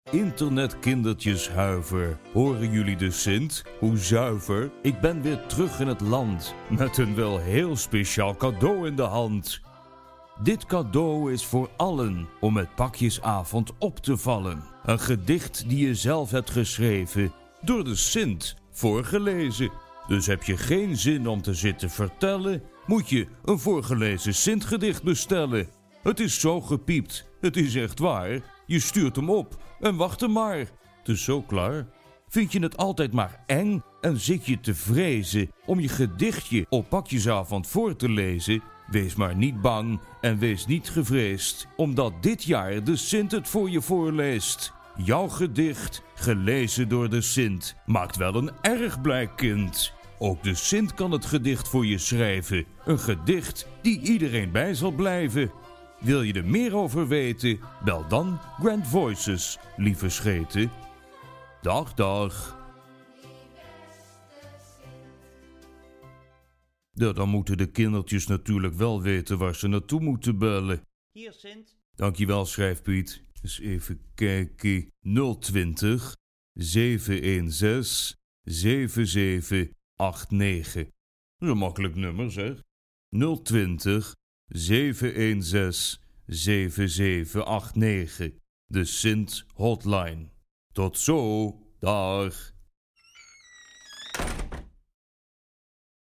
Voorgelezen-Sintgedicht.mp3